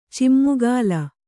♪ cimmugāla